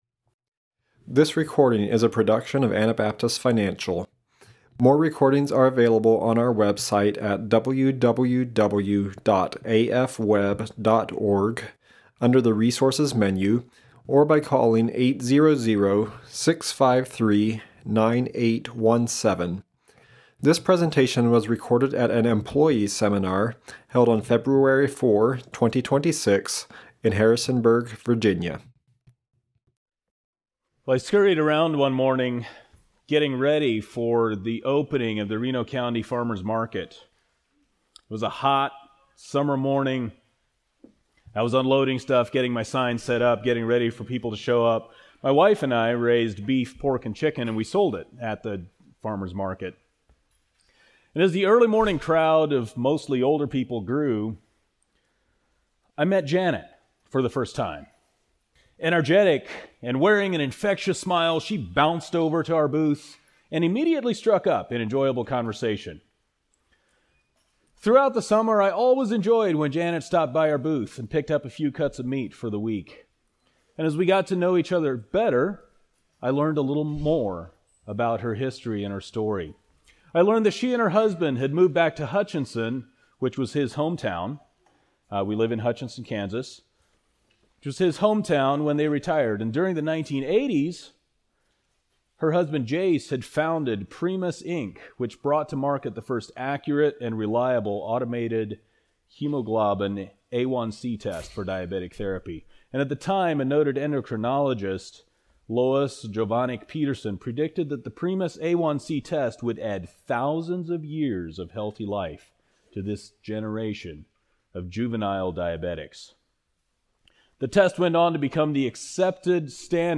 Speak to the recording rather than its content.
Virginia Employee Seminar 2026